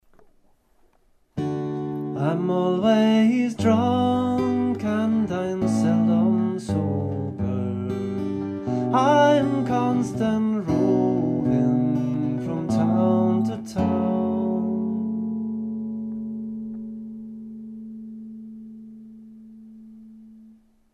Finger picked + Vox
ai_ten2_fingerpicked_vox.mp3